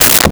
Briefcase Latch
Briefcase Latch.wav